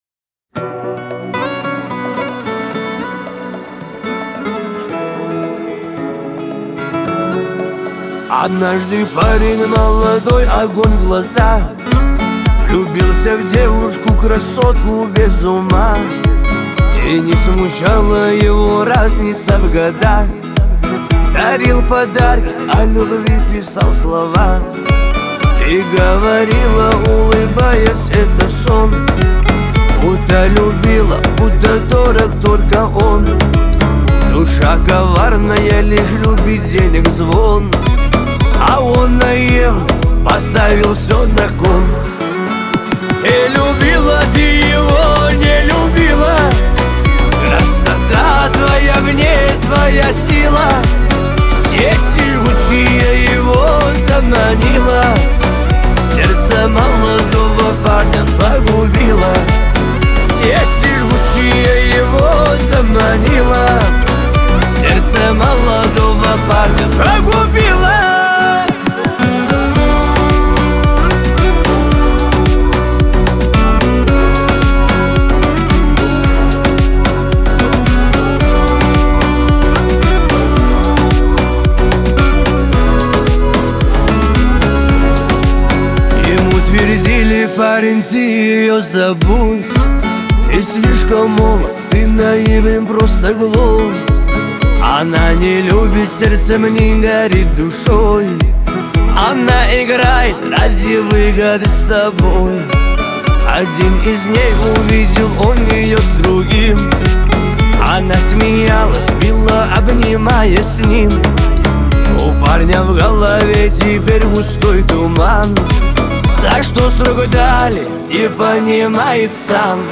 Лирика
Кавказ – поп